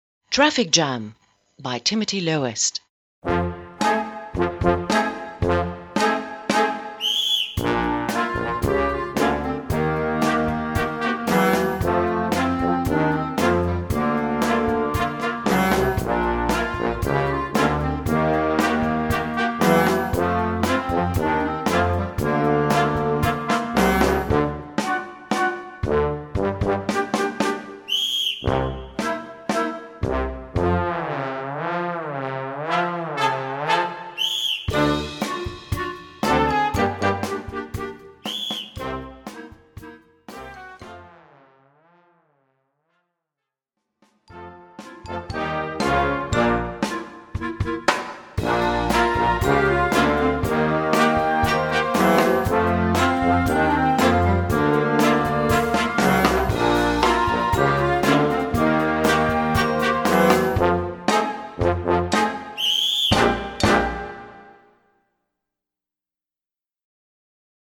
Gattung: Werk für Jugendblasorchester
Besetzung: Blasorchester